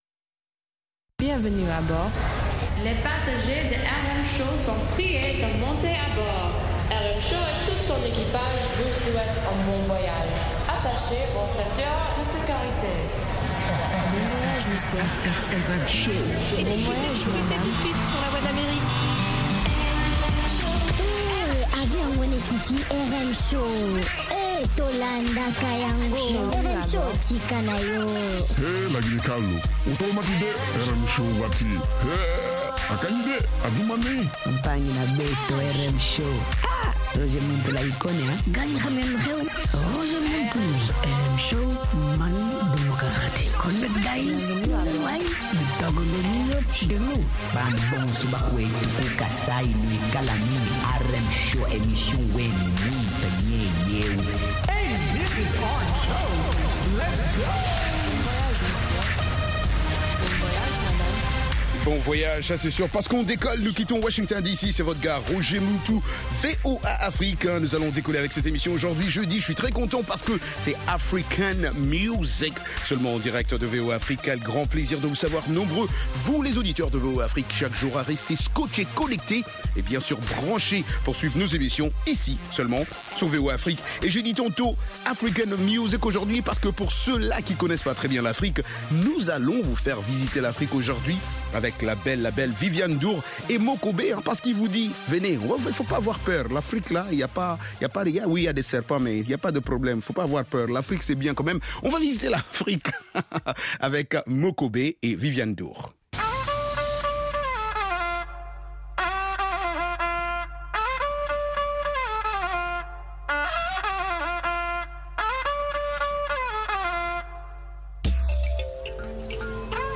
propose notamment d'écouter de la musique africaine, des articles sur l'actualité Afro Music, des reportages et interviews sur des événements et spectacles africains aux USA ou en Afrique.